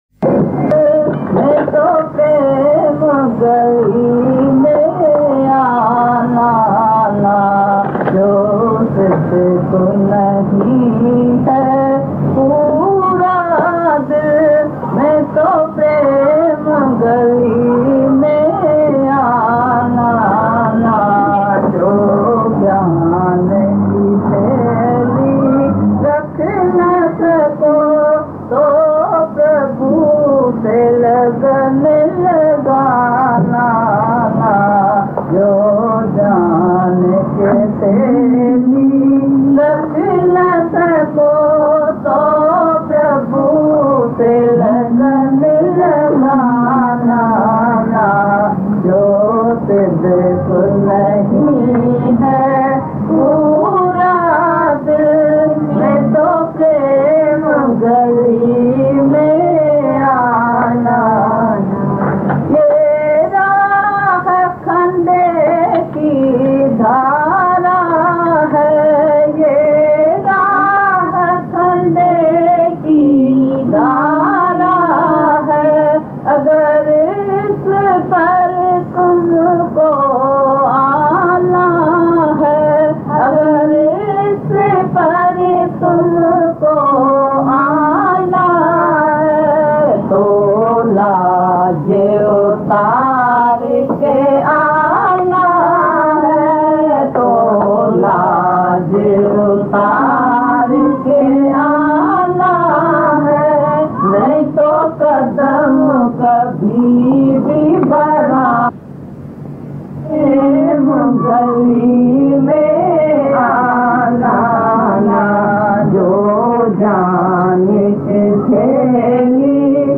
Jo-Siddhak-Nahi-Hai-Pura-Dill-Mein-to-Prem-Bhajan.mp3